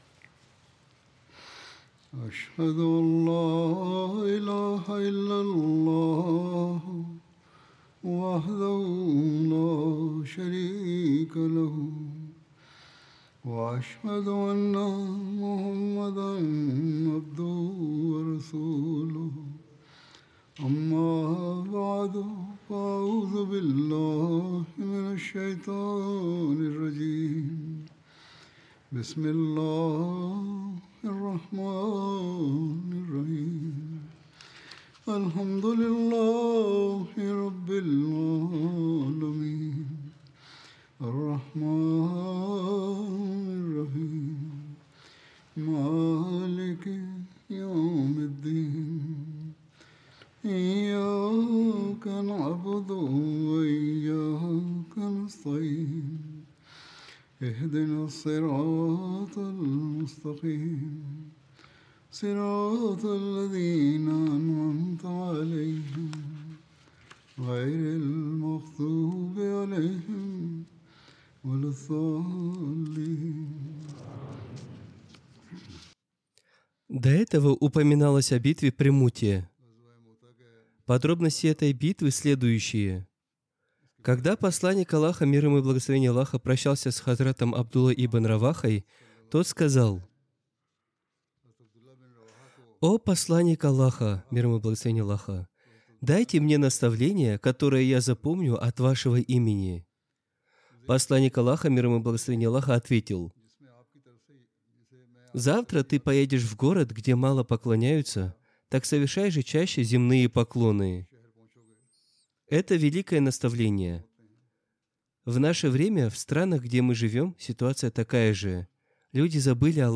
Russian translation of Friday Sermon delivered by Khalifa-tul-Masih on April 18th, 2025 (audio)